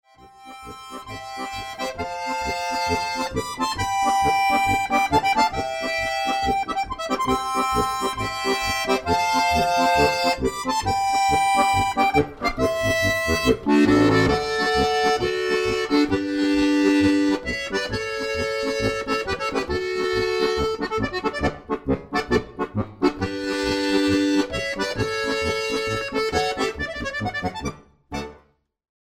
• für 4-reihige Harmonika